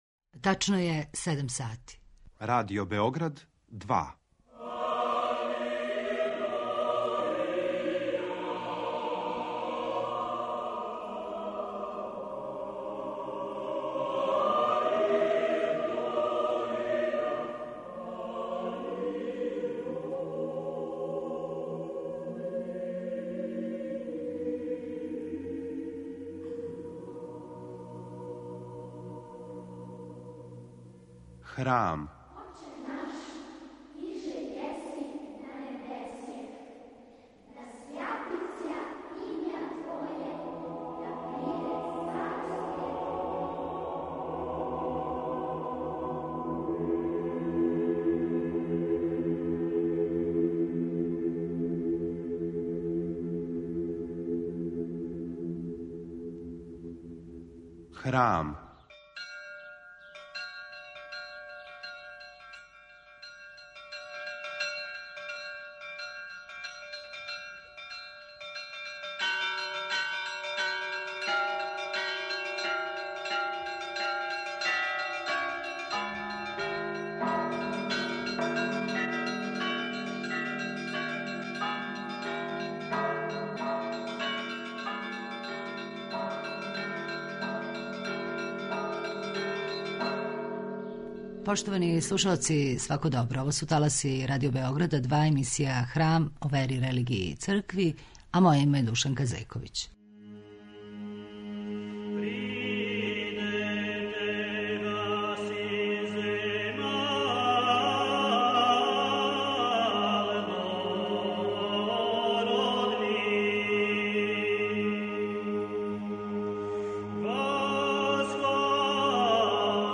Читање Библије - Нови завет